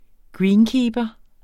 Udtale [ ˈgɹiːnˌkiːbʌ ]